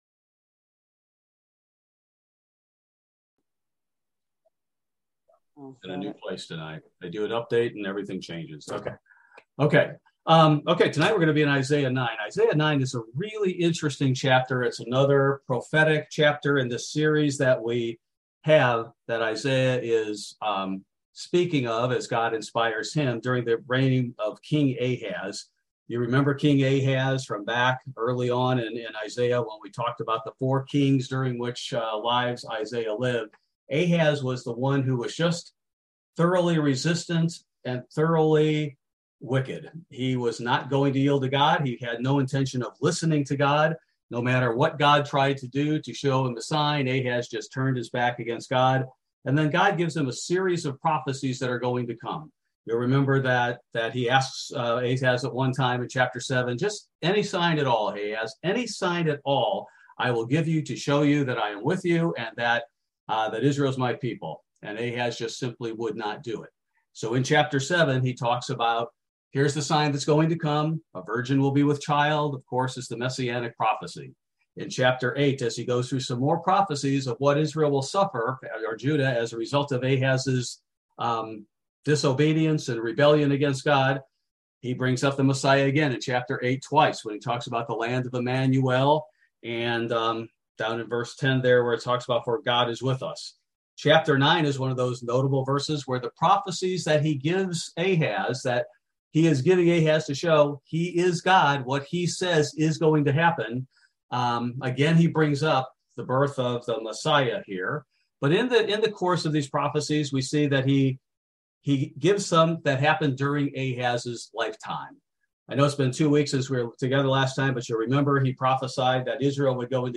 Bible Study: August 24, 2022